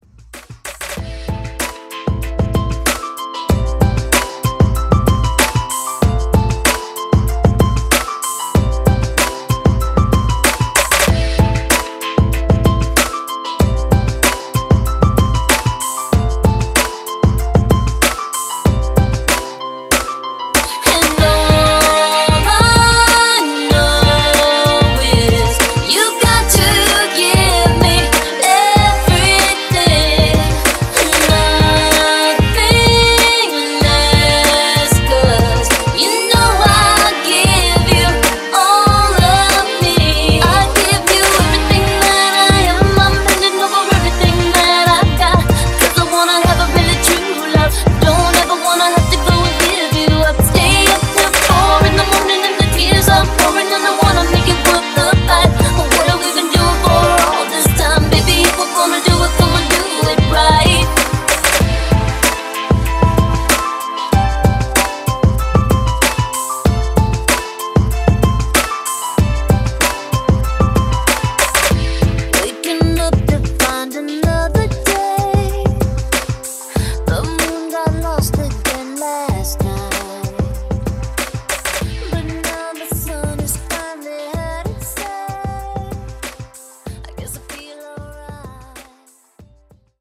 Genre: 2000's
Clean BPM: 105 Time